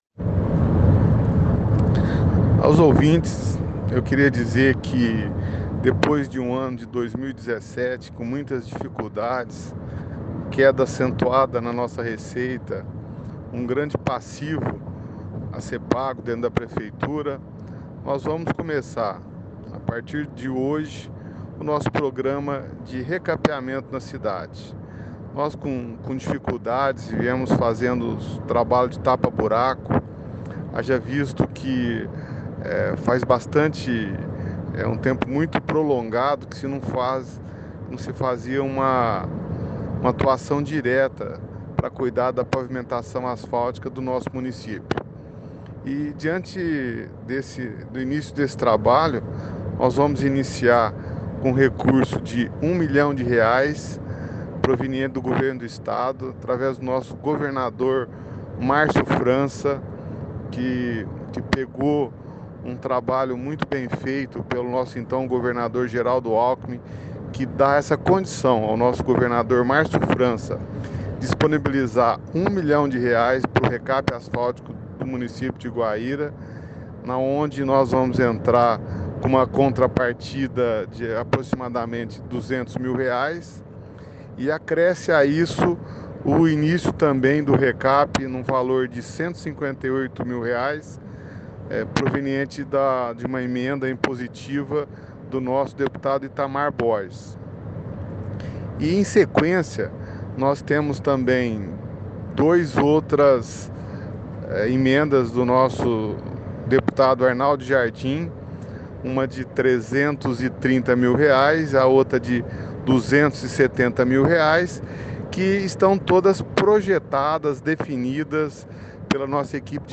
Ouça entrevista do Prefeito de Guaíra, José Eduardo C. Lélis, sobre o recape da cidade: